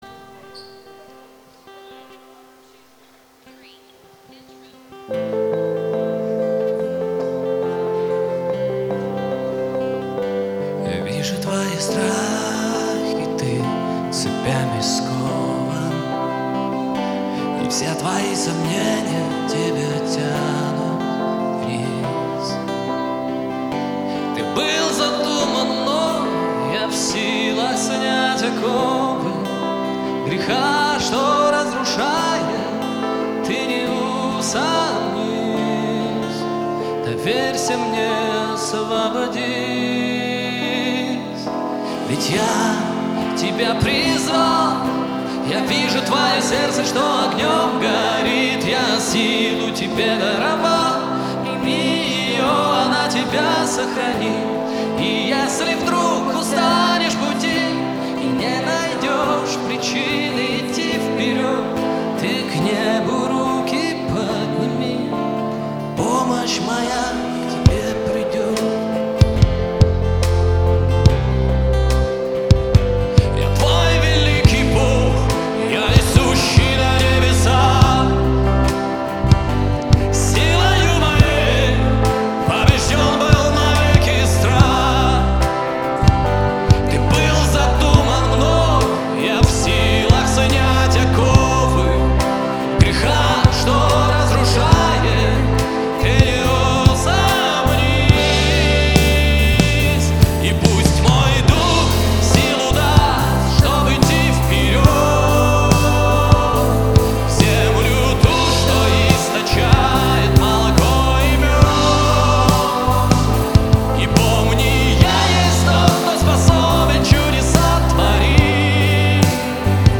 11 просмотров 7 прослушиваний 1 скачиваний BPM: 142 4/4